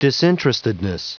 Prononciation du mot disinterestedness en anglais (fichier audio)
Prononciation du mot : disinterestedness